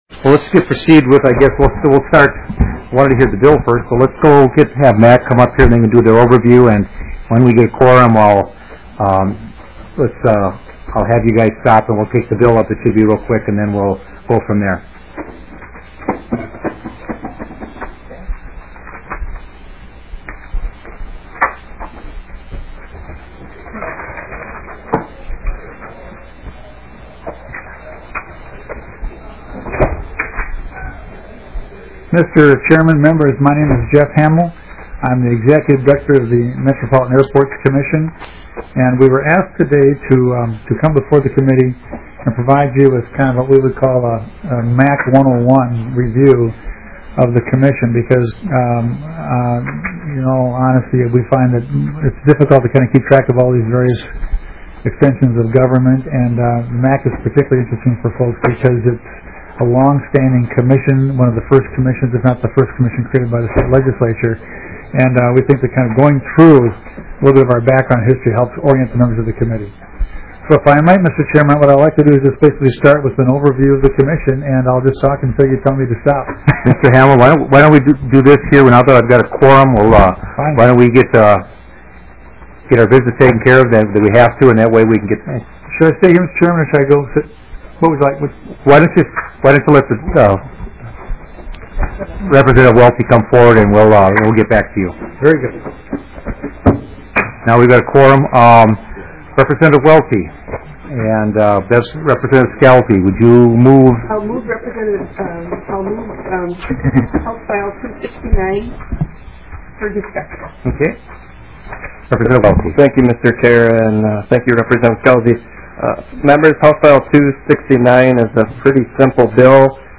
Representative Michael Nelson, Chair of the Division, called the third meeting to order at 4:32 P.M. on February 9, 2009, in Room 200 of the State Office Building.